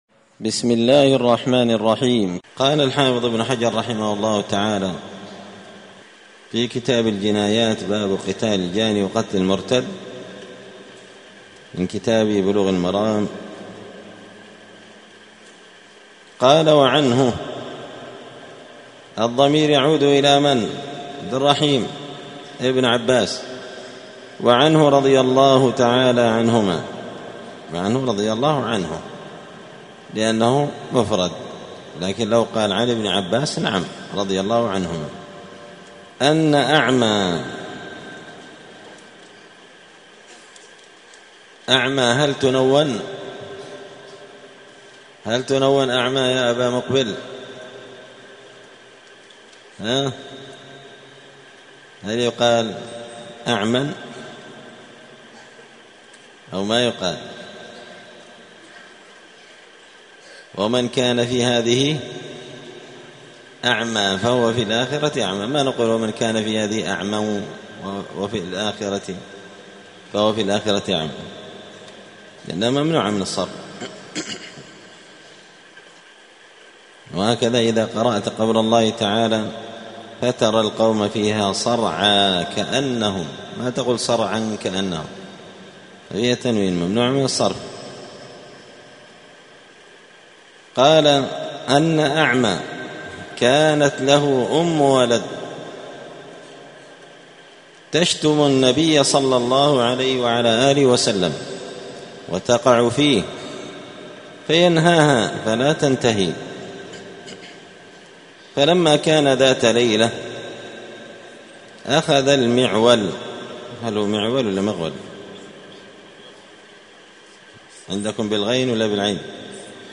*الدرس الحادي والأربعون (41) {باب قتل من سب النبي وإهدار دمه}*